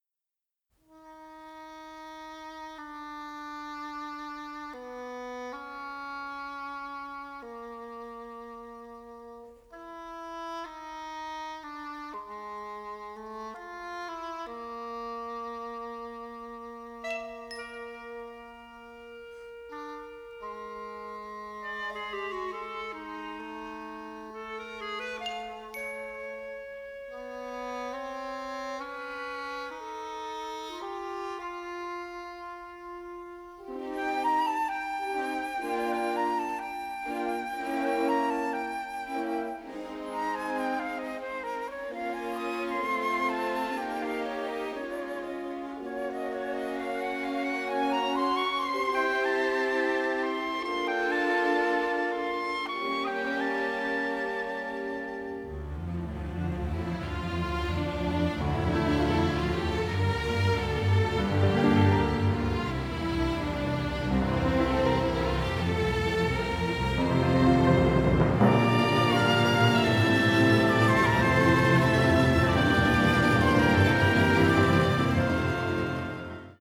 composed for full orchestra.